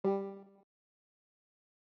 scoge-menu1-move-4.wav